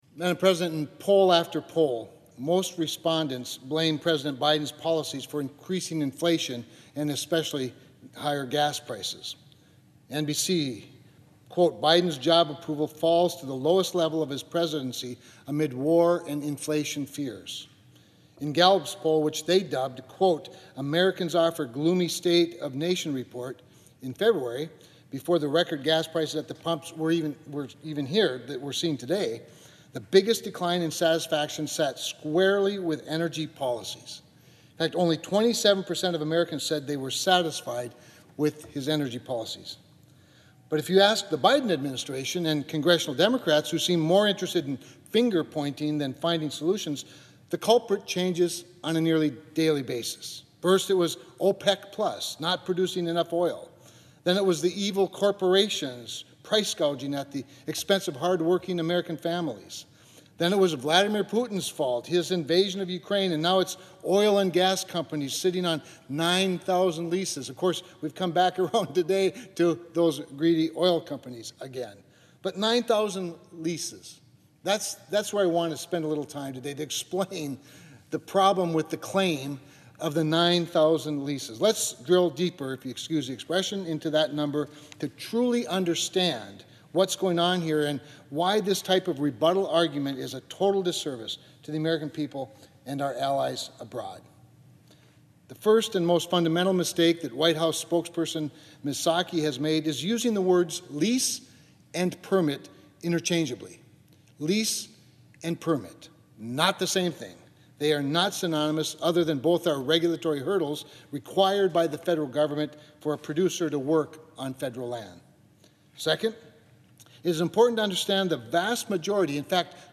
4.6-Lease-Lie-Floor-Speech.mp3